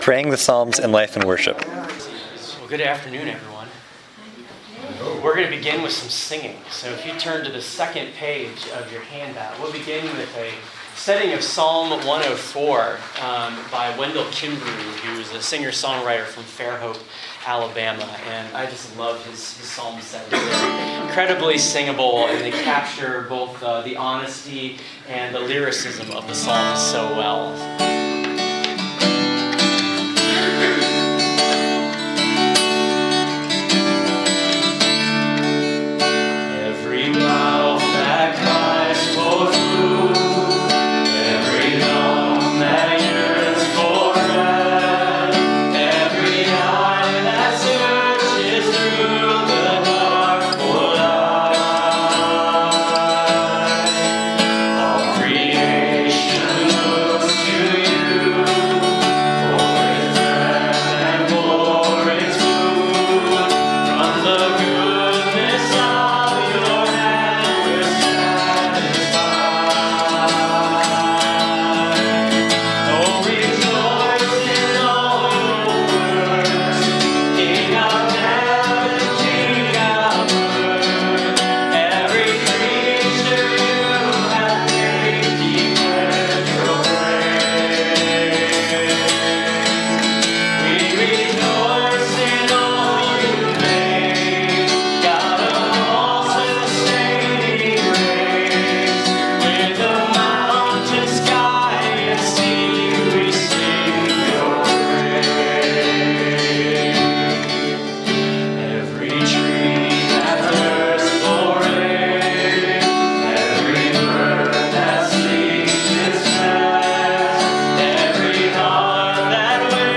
In this workshop a church planting pastor and a campus minister tell of the vitality and relevancy of the Psalms in their worshiping communities. They also share experiences and examples from daily life and corporate worship and provide ample opportunity to listen and learn.